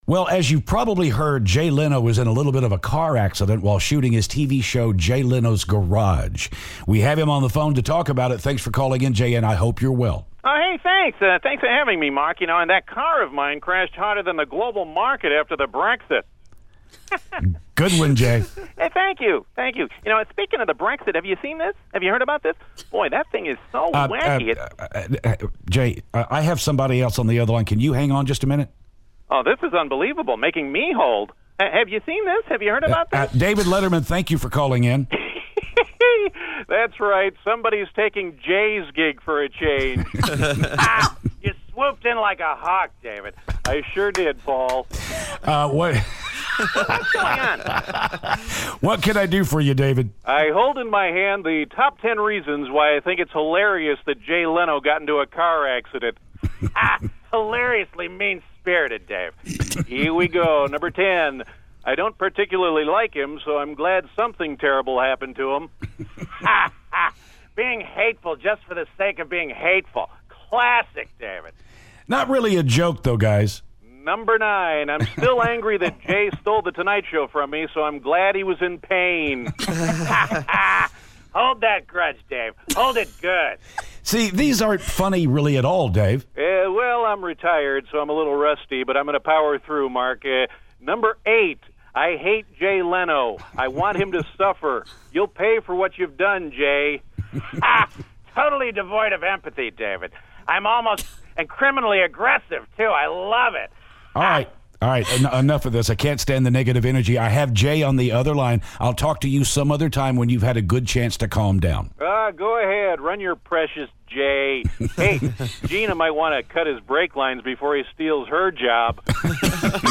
Jay Leno Phoner
Jay Leno calls the show to talk about his car accident.